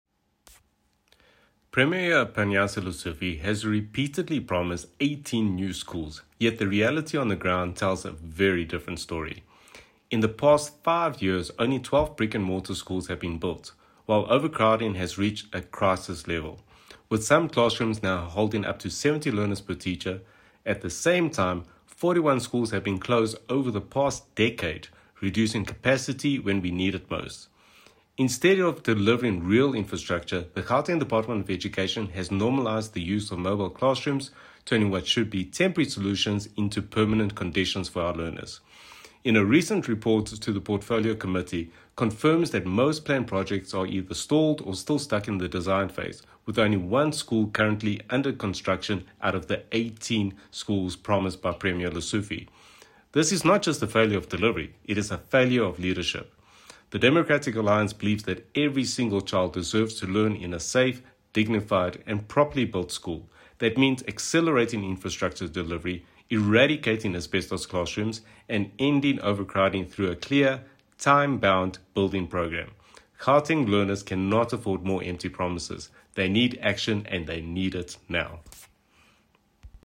soundbite by Sergio Isa Dos Santos MPL.